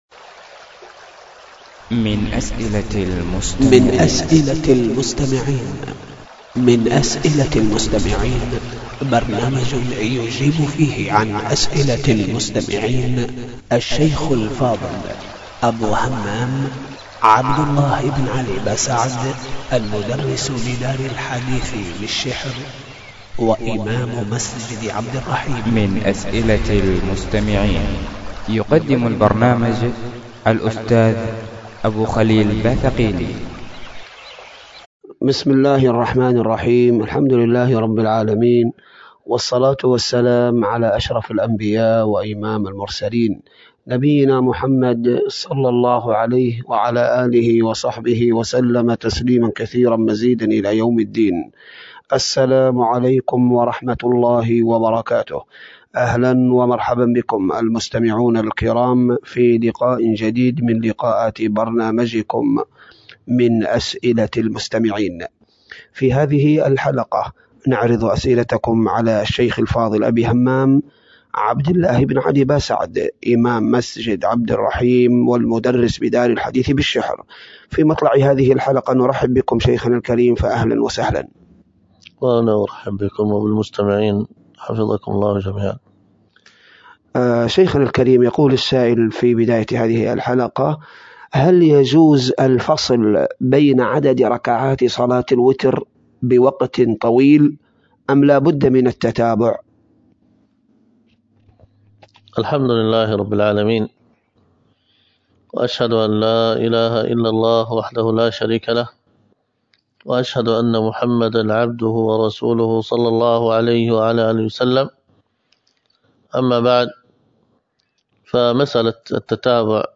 الخطبة بعنوان ورضوان من الله أكبر، وكانت بمسجد التقوى بدار الحديث بالشحر ١٥ جمادى الأخر ألقاها